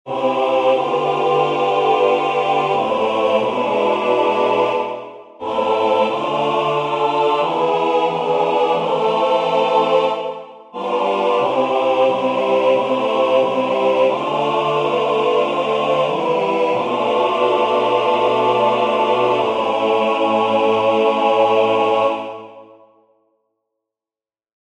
Key written in: A Minor
How many parts: 4
Type: Barbershop
All Parts mix: